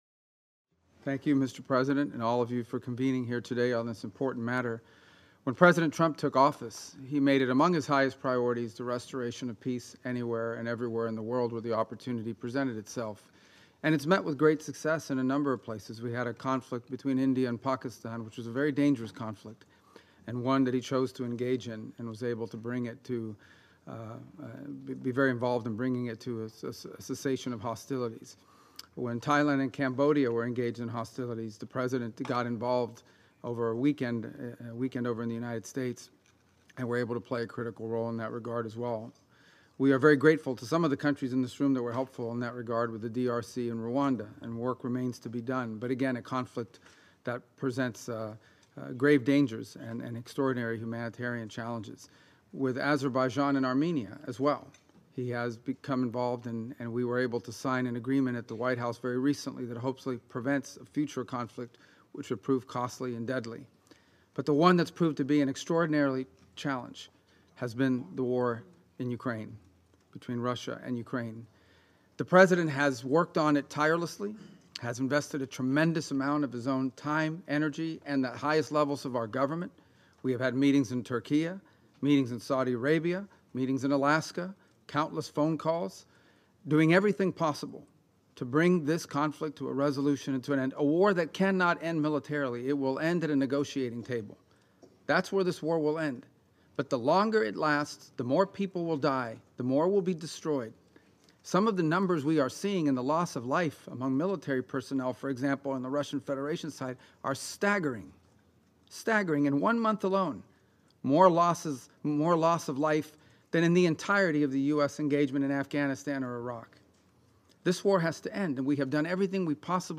Remarks to a United Nations Security Council Ministerial Meeting on Ukraine
delivered 23 September 2025, UN Headquarters, New York, NY